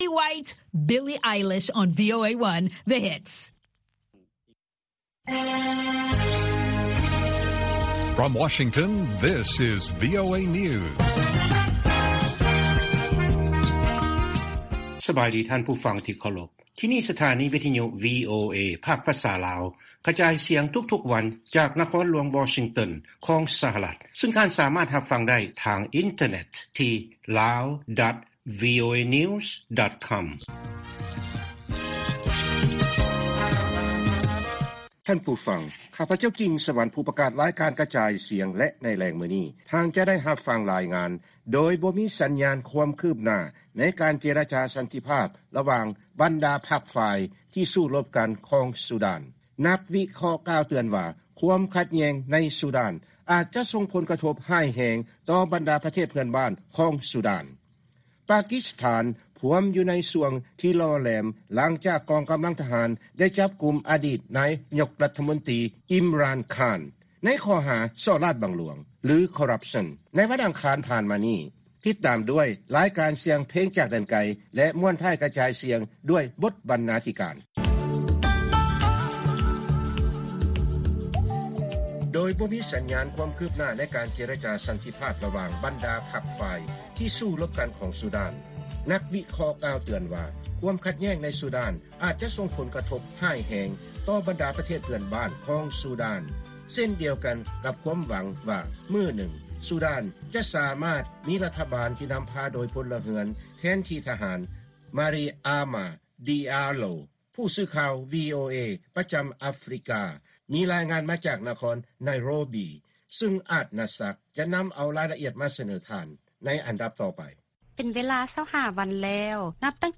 ລາຍການກະຈາຍສຽງຂອງວີໂອເອ ລາວ: ການສູ້ລົບກັນຢູ່ໃນຊູດານ ເຮັດໃຫ້ປະເທດເພື່ອນບ້ານຕົກຢູ່ໃນຄວາມສ່ຽງ